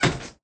foodbutton.ogg